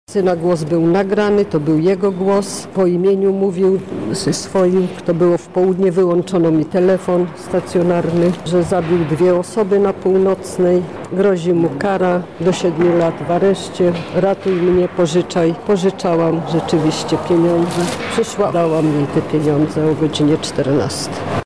Jak wyglądały ich działania tłumaczy jedna z poszkodowanych, która wolała pozostać anonimowa: